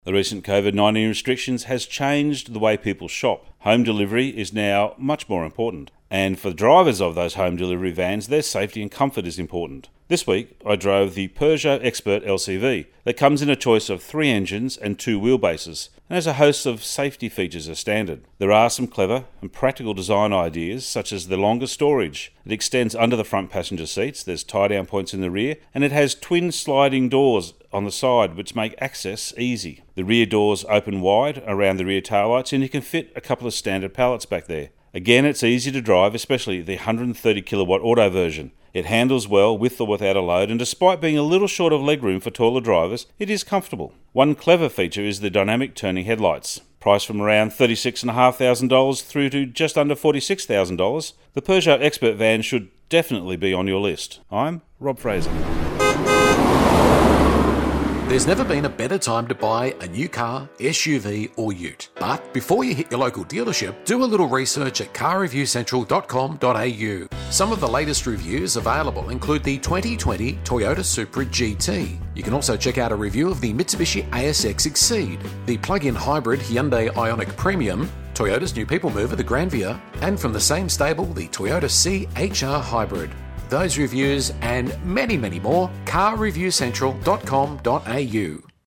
Motoring Minutes are heard around Australia every day on over 50 radio channels through the Torque Radio networkMotoring Minutes have an average daily audience of over 150,000 listeners.